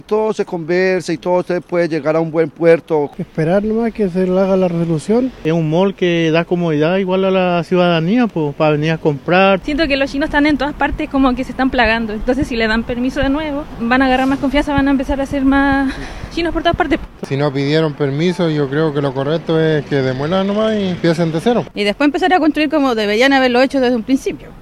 Esta determinación dejó distintas reacciones en transeúntes que día a día ven una estructura a medio terminar en pleno centro de Valdivia.